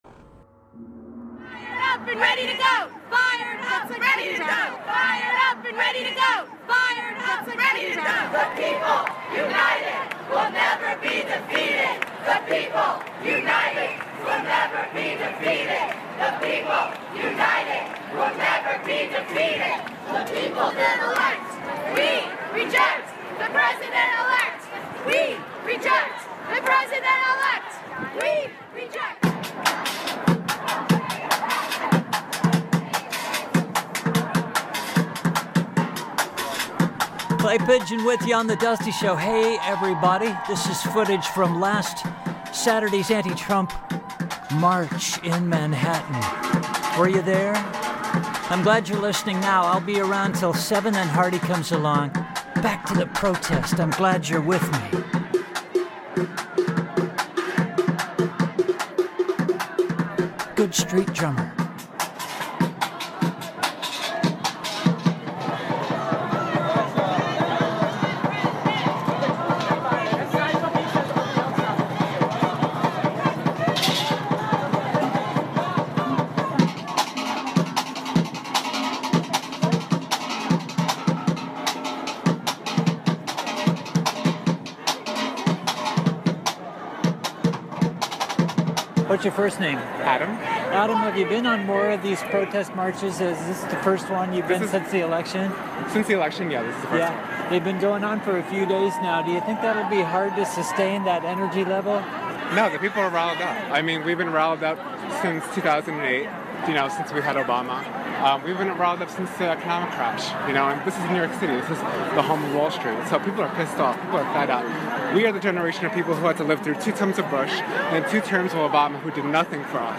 Set: Audio from Nov 12th - Anti-Trump March in Manhattan - Shanting and Street Drummer
Set: City Jackhammer